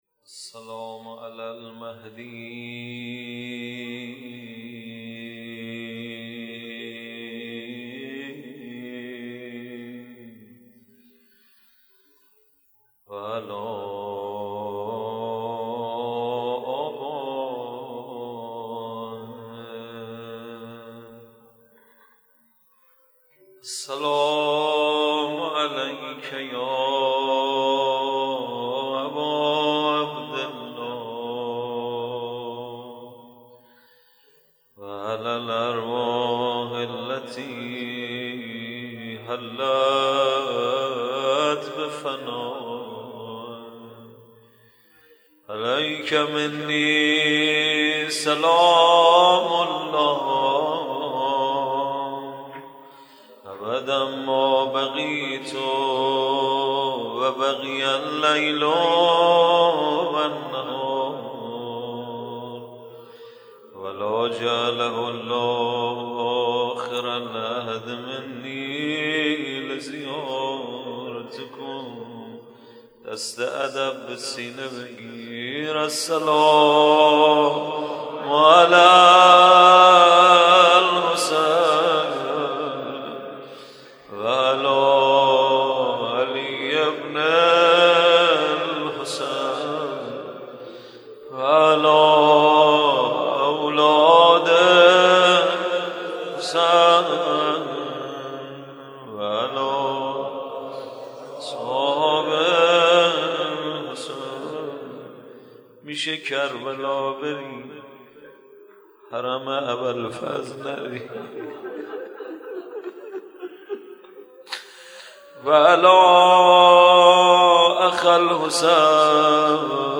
مداحی شب پنجم محرم ۹۶ - هیئت ثارالله قم
در مدرسه مبارکه فیضیه
روضه
rozemaddah.mp3